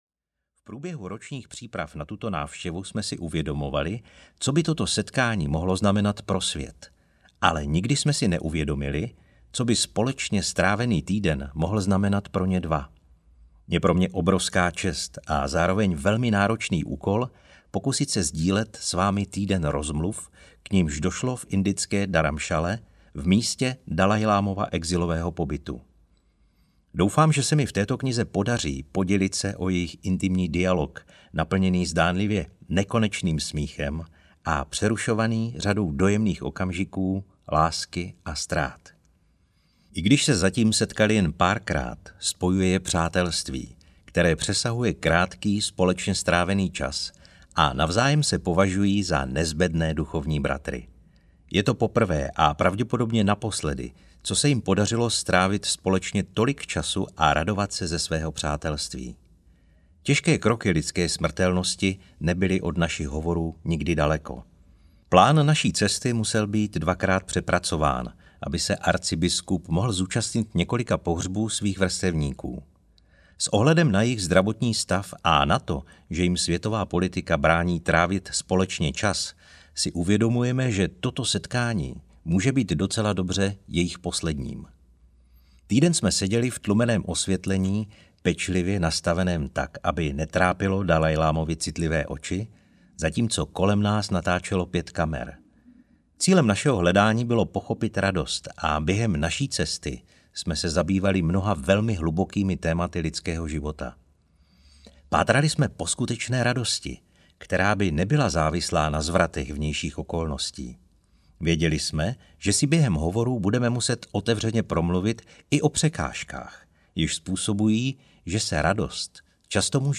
Kniha radosti audiokniha
Ukázka z knihy
• InterpretPavel Kašpar